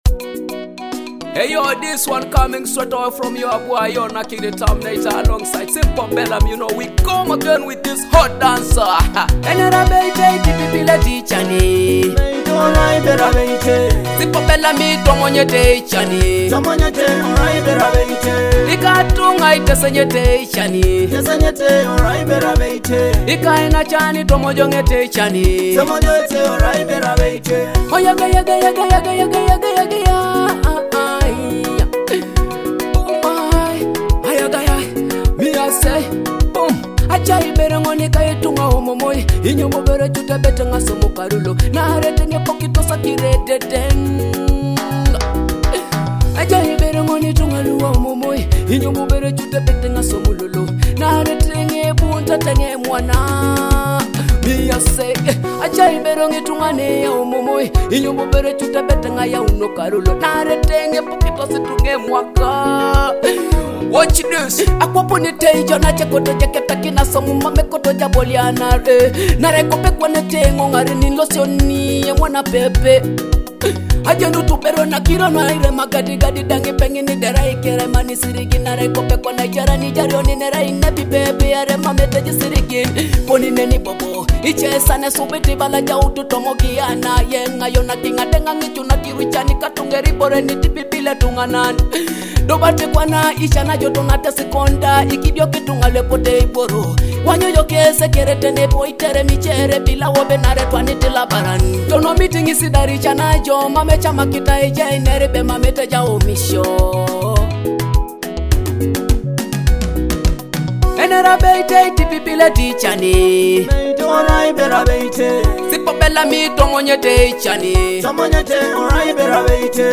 your source for authentic Teso music.